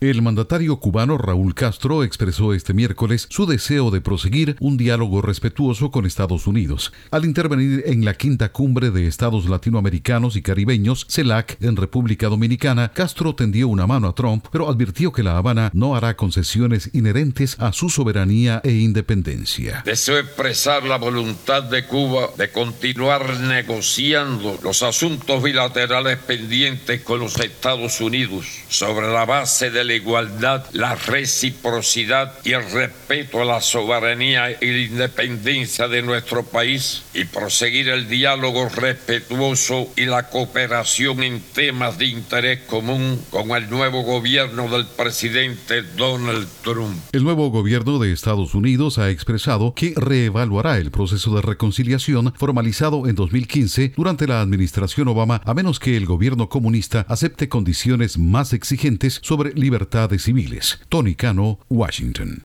Intervención de Raúl Castro en cumbre de la CELAC